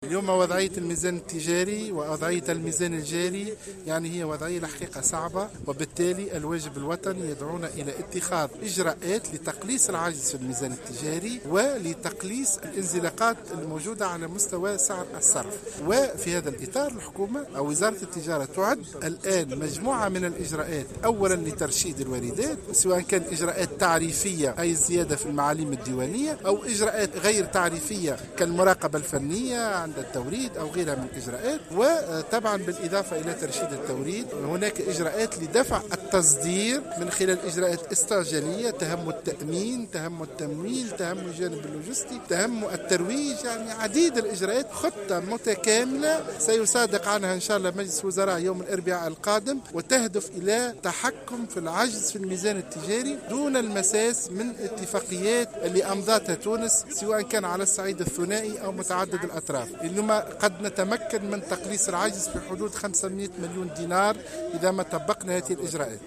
وقال حسن خلال زيارته ، الجمعة، السوق البلدي باريانة انه سيتم اعداد خطة واضحة لتحقيق هذا الهدف وذلك بالتعاون مع مختلف الوزارات بعد ان بلغ عجز الميزان التجاري والميزان الجاري مستويات "صعبة".